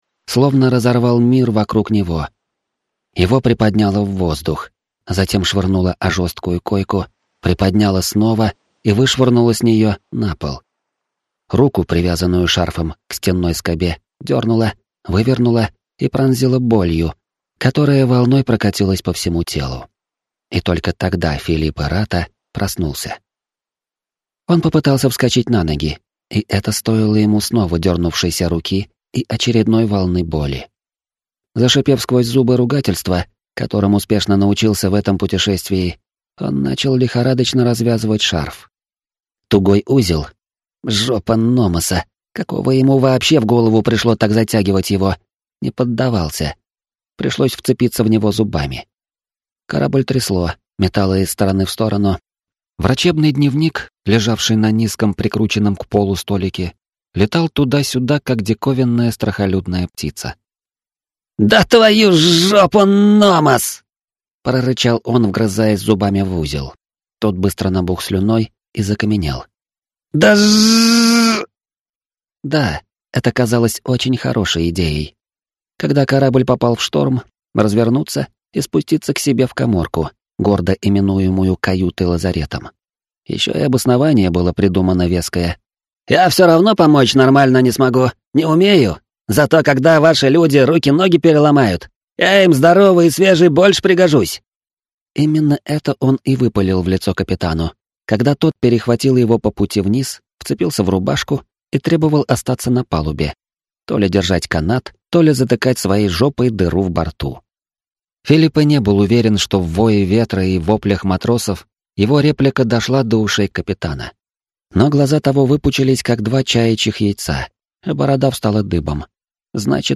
Аудиокнига Аква 6. Соль и вода | Библиотека аудиокниг